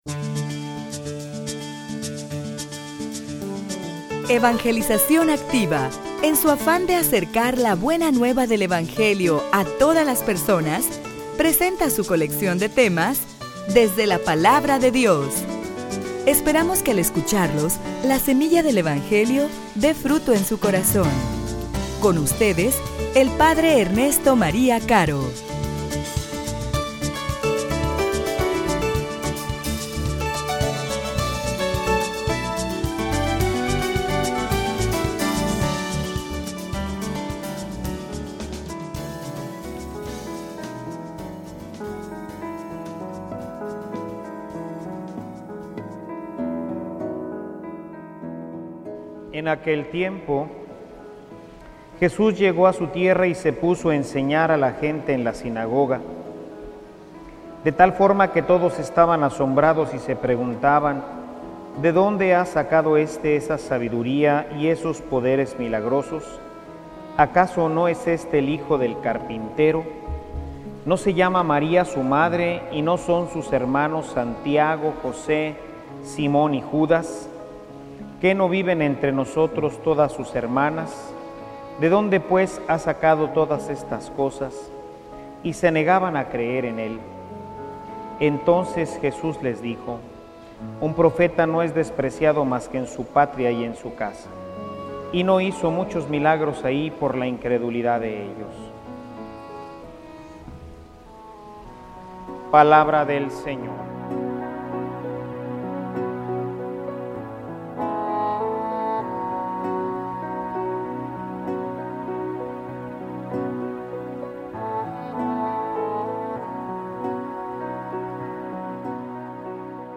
homilia_La_Salud_del_Alma.mp3